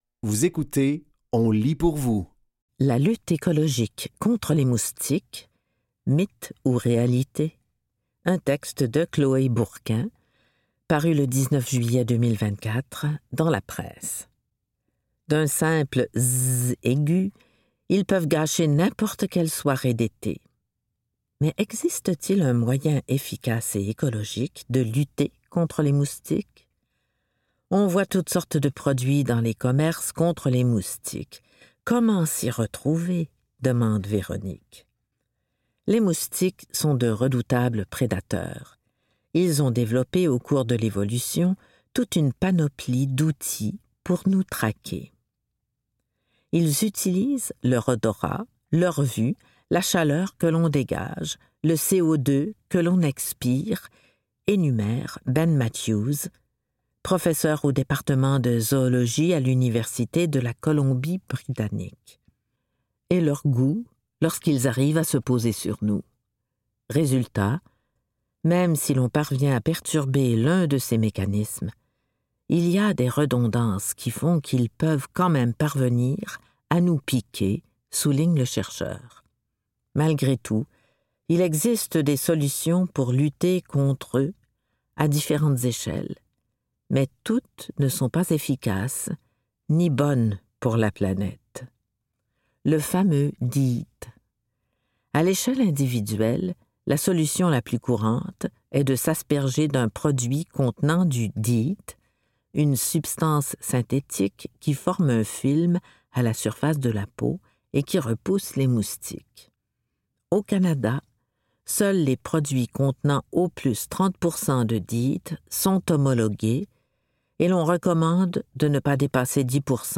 Dans cet épisode de On lit pour vous, nous vous offrons une sélection de textes tirés des médias suivants :La Presse, Le Devoir et La Source.